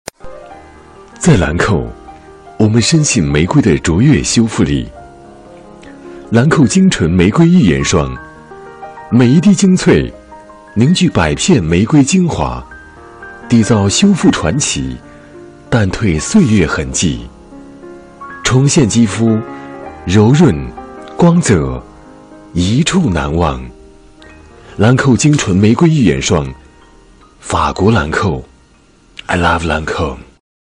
男国32_广告_化妆品_兰蔻_优雅.mp3